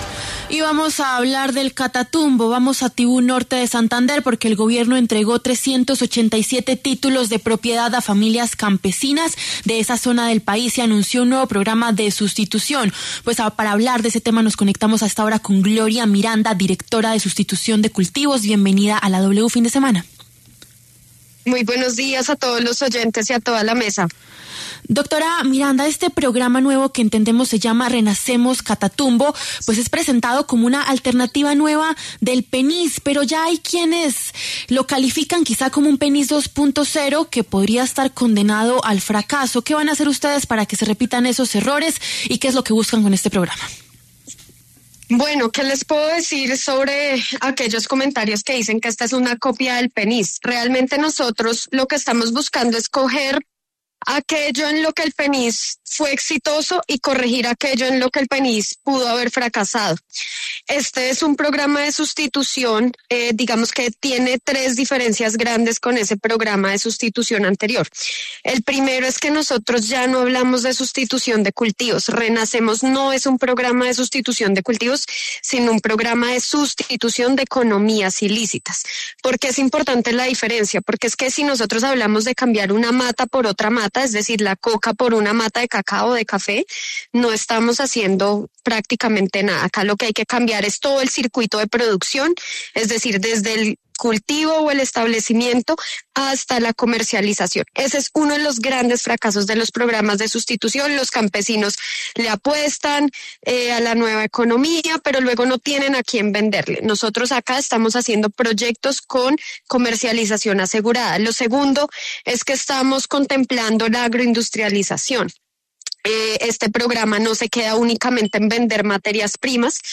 Para dar detalles del proceso, habló en los micrófonos de W Fin De Semana Gloria Miranda, directora de Sustitución de cultivos de uso ilícito en Colombia.